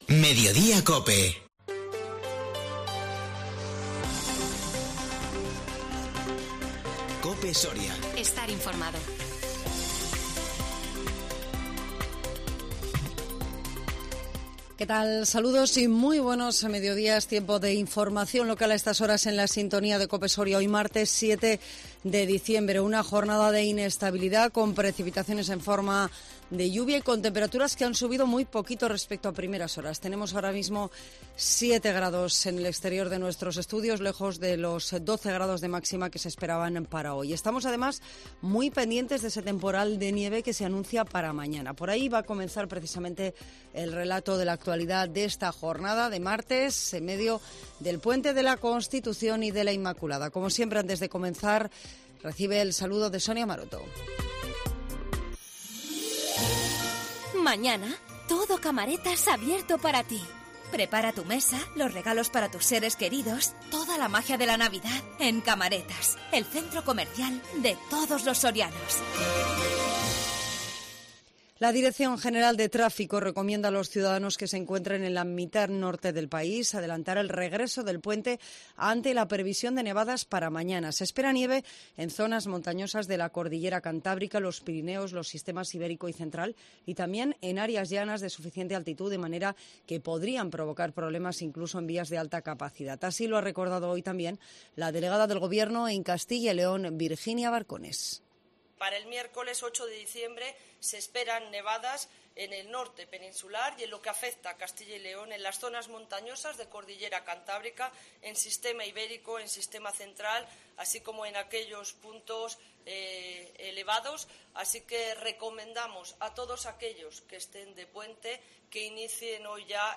INFORMATIVO MEDIODÍA 7 DICIEMBRE 2021